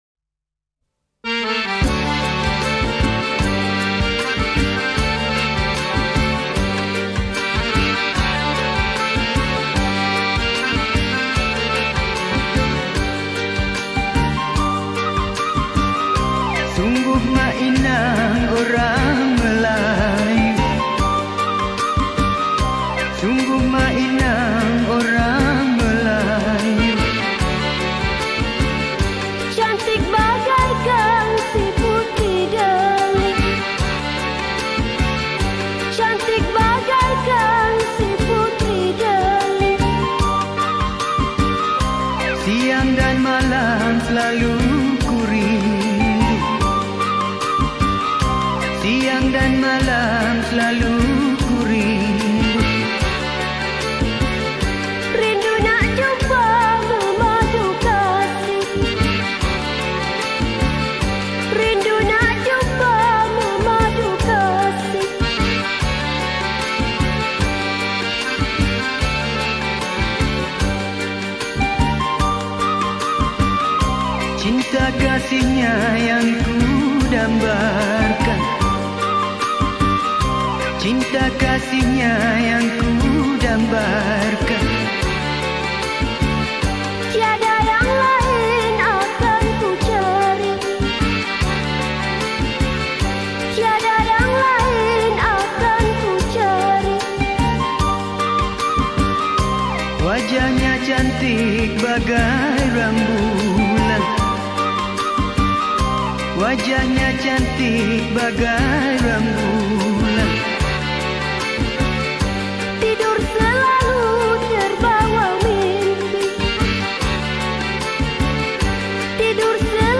Malay Songs
Malay Old Folk Song